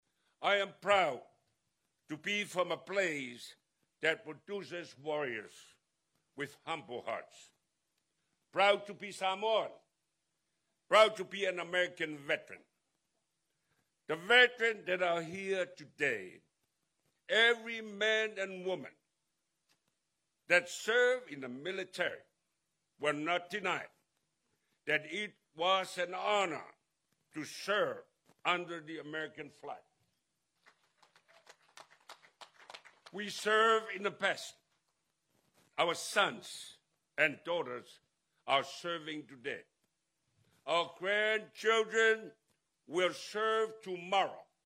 The territory paid tribute to veterans of all branches of the U.S. Armed Forces at the 2025 Veterans Day celebration at the Veterans Monument Field on Tuesday.
The selected guest speaker, Retired Army CSM Senator Gaoteote Palaie Tofau, highlighted that American Samoa has the highest per capita enlistment rate among all states and territories. He said veterans have returned home to continue serving their families, government and territory.